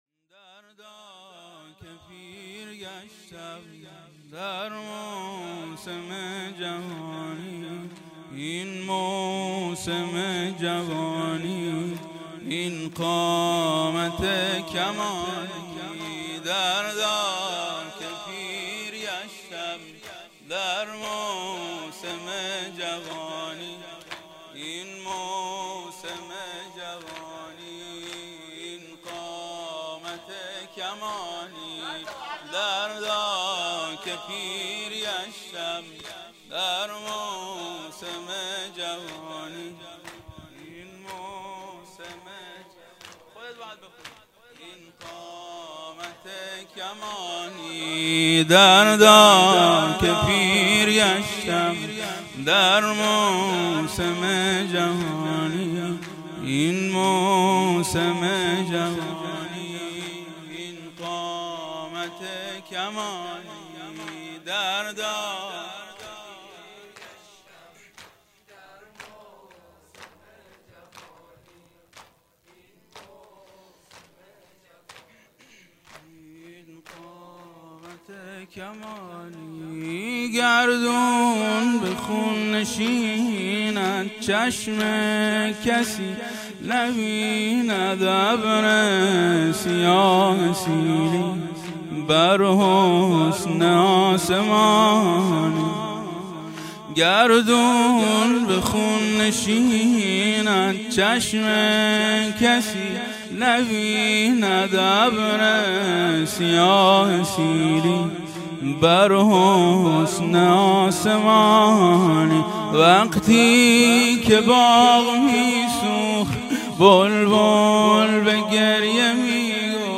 زمینه/دردا که پیر گشتم در موسم جوانی/فاطمیه99 هیئت ناصرالحسین